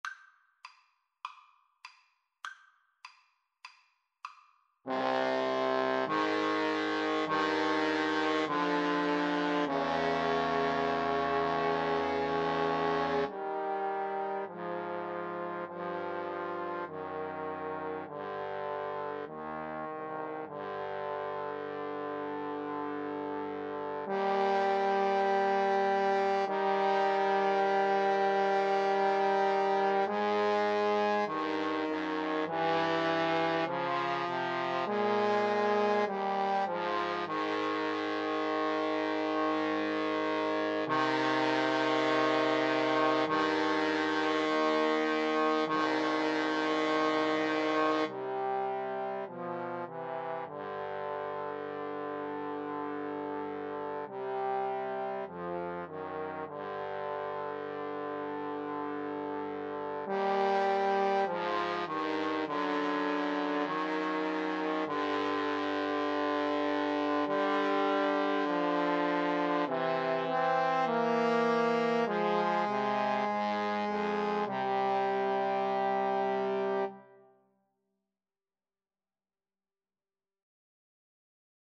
Eb major (Sounding Pitch) (View more Eb major Music for Trombone Trio )
Trombone Trio  (View more Easy Trombone Trio Music)
Classical (View more Classical Trombone Trio Music)